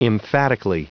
Prononciation du mot emphatically en anglais (fichier audio)
Prononciation du mot : emphatically